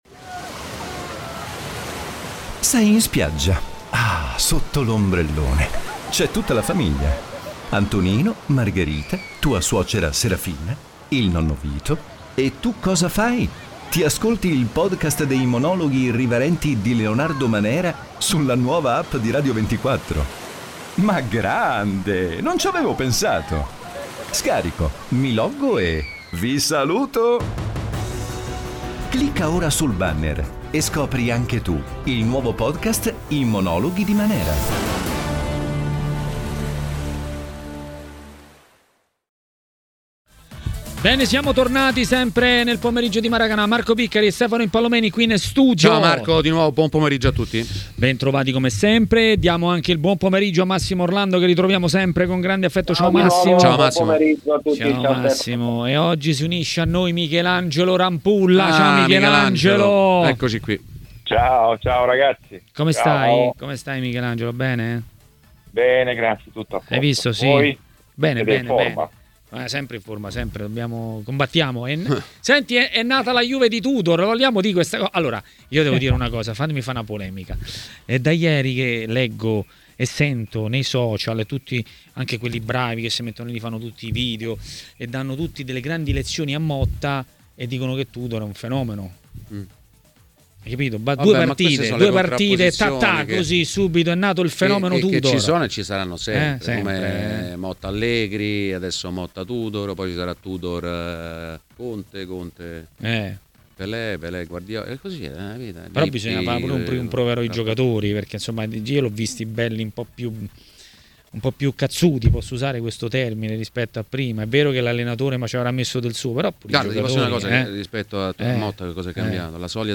A commentare la Juventus a Maracanà, nel pomeriggio di TMW Radio, è stato l'ex portiere Michelangelo Rampulla.